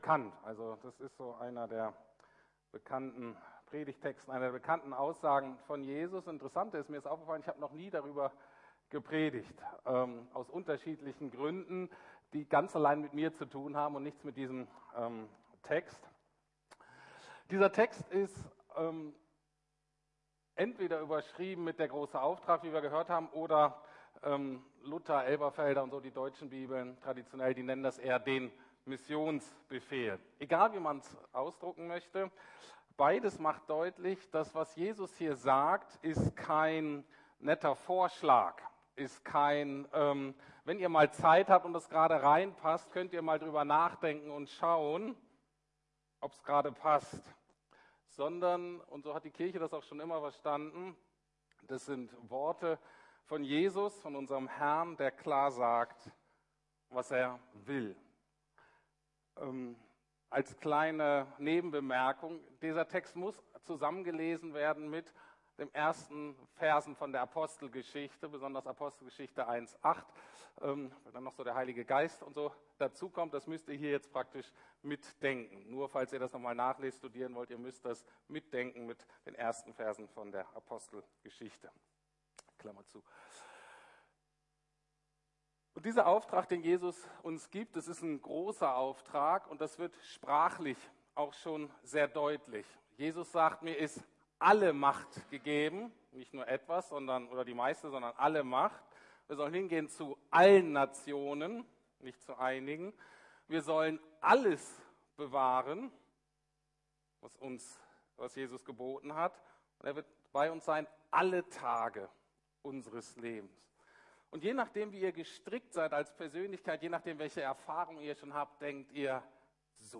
Der große Auftrag ~ Predigten der LUKAS GEMEINDE Podcast